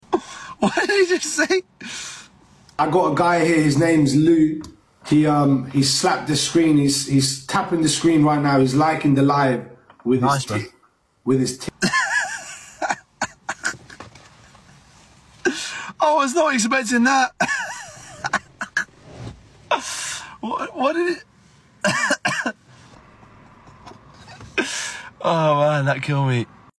Errr… that was an unexpected moment 🤣 on LIVE.